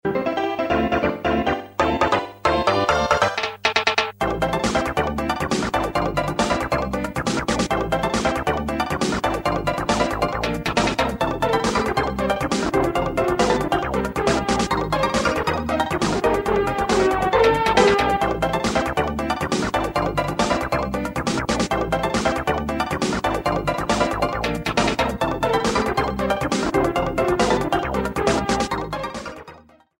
30 seconds and fadeout You cannot overwrite this file.